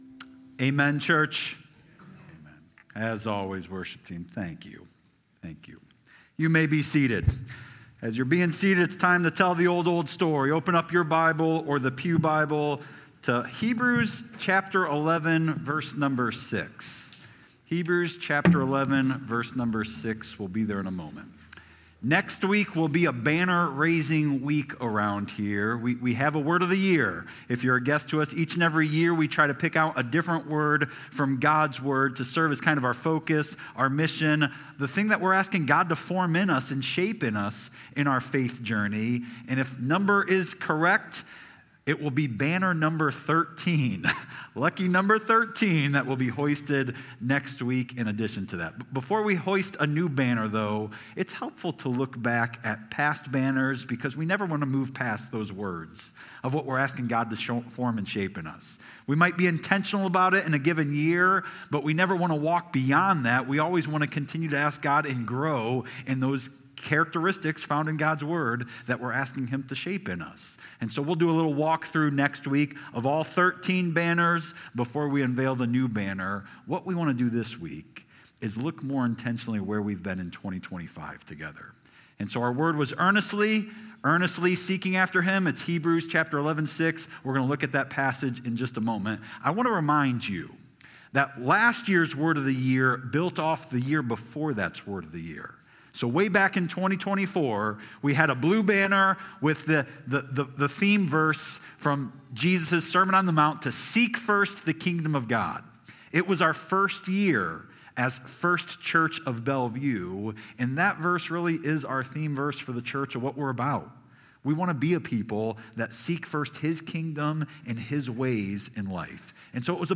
Sermons | First Church Bellevue